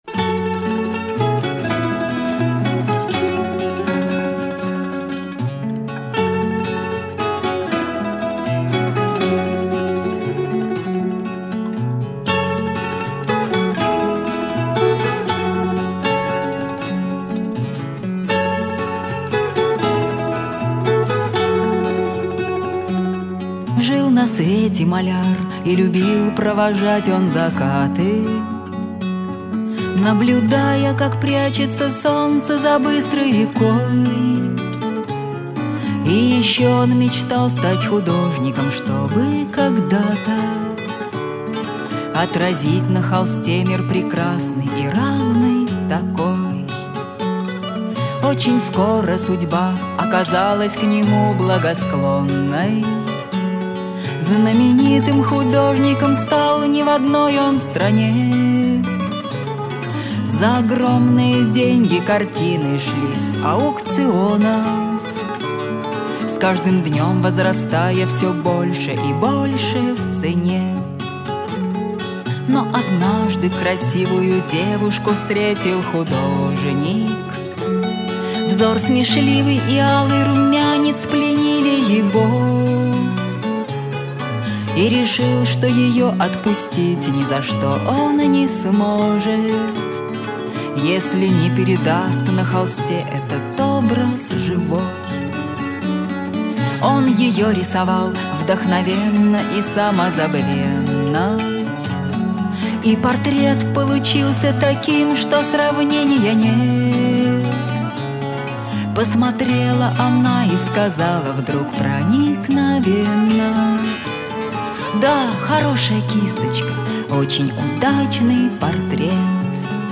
гитара, мандолина.